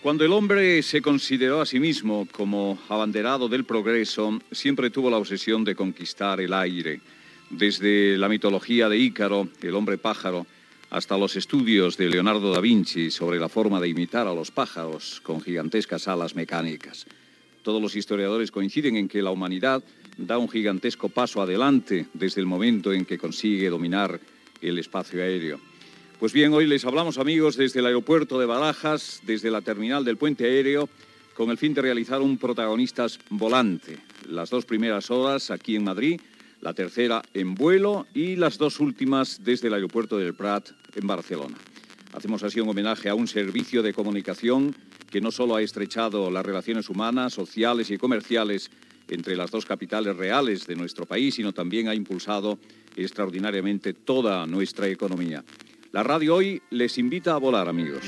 Programa emès des d'un Boeing 727 d'Iberia. Des del terminal del pont aeri Bardelona Madrid a l'aeroport de Barajas.
Info-entreteniment